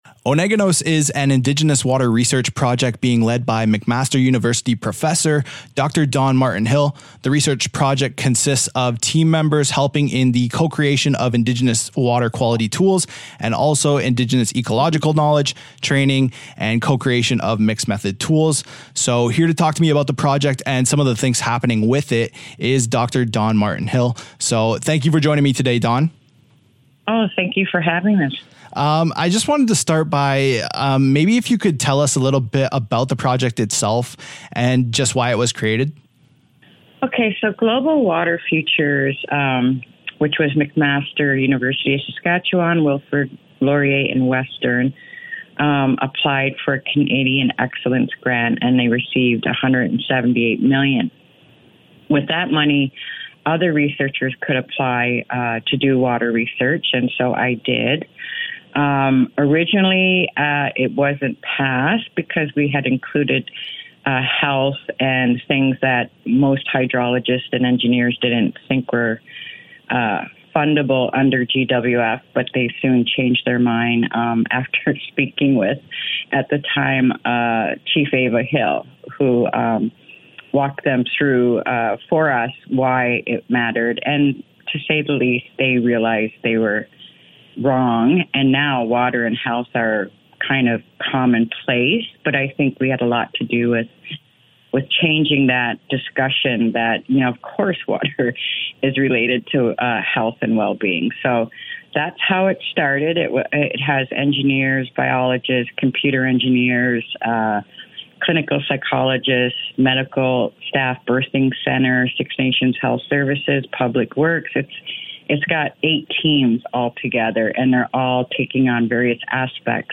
CJKS - Ohsweken